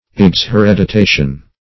Search Result for " exhereditation" : The Collaborative International Dictionary of English v.0.48: Exhereditation \Ex`he*red`i*ta"tion\, n. [LL. exhereditare, exhereditatum, disinherit.]